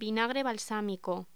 Locución: Vinagre balsámico
voz